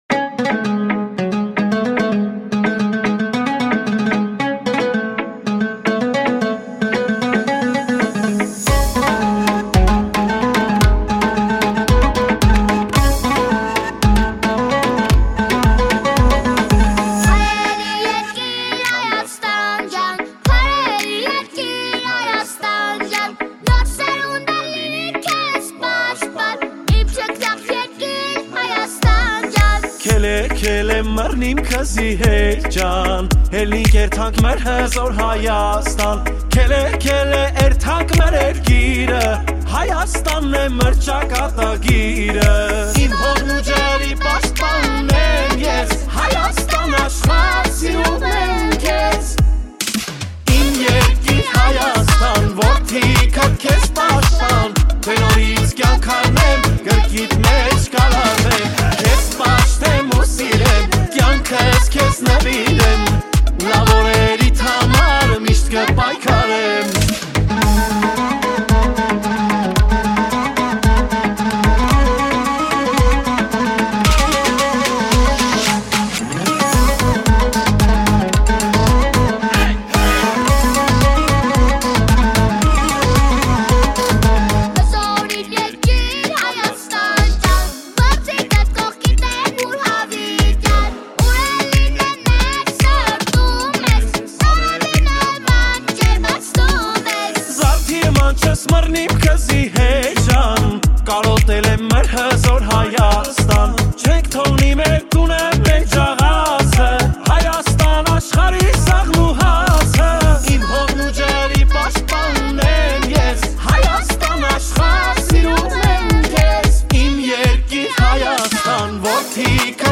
Армянская музыка, Дуэт